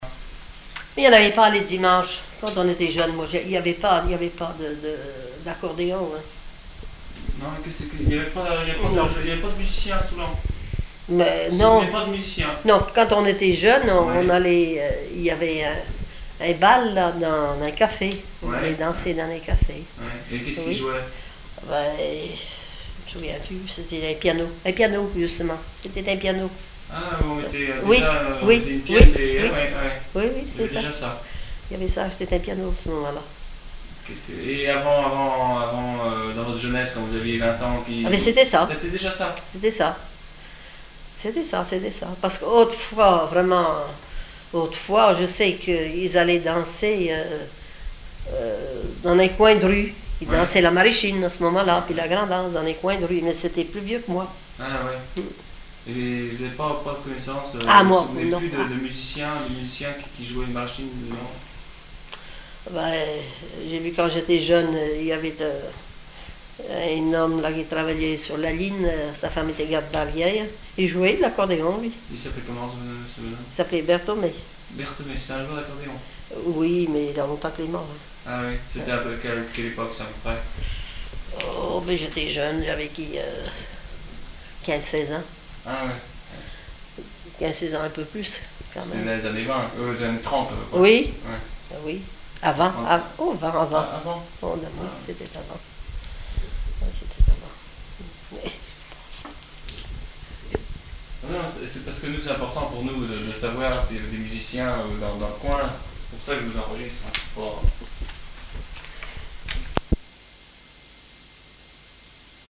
Enquête sur les chansons populaires
Catégorie Témoignage